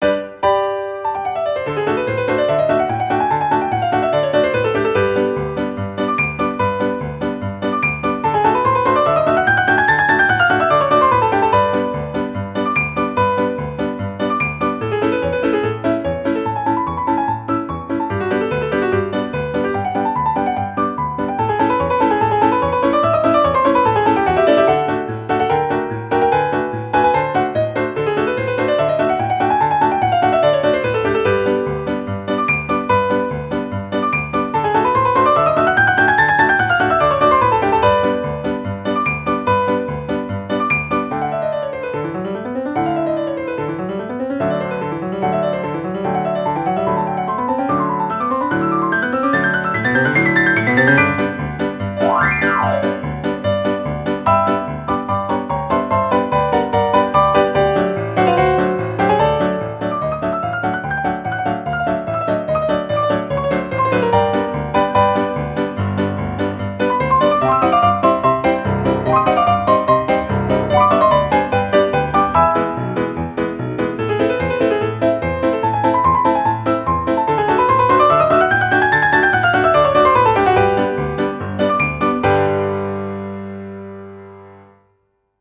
ogg(R) - 疾走 楽しい 激しい
映画のコミカルシーンのようなダイナミックで楽しいピアノ。